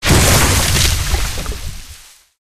drudge_body_explosion01.mp3